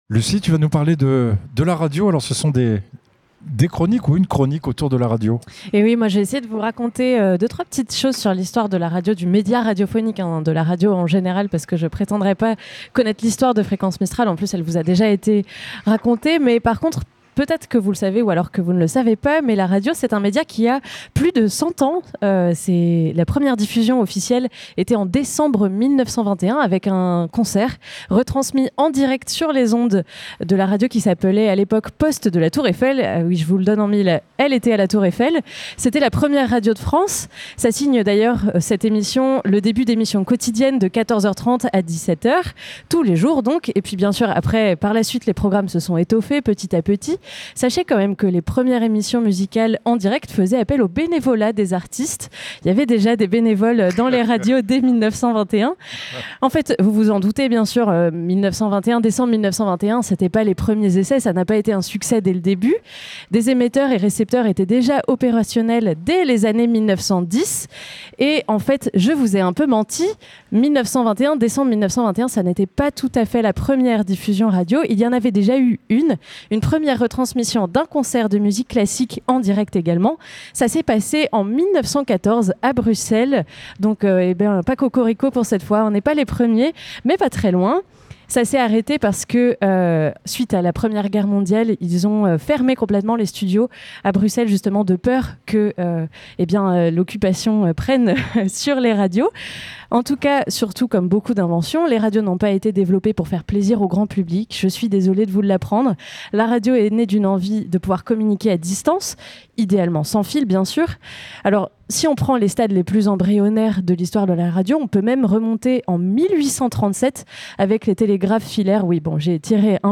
Le 5 et 6 juin 2025 c'était la fête de la Radio dans toute la France. A cette occasion, toute l'équipe de Fréquence Mistral s'est retrouvée afin de vous proposer un plateau délocalisé en direct sur toute la journée sur Manosque.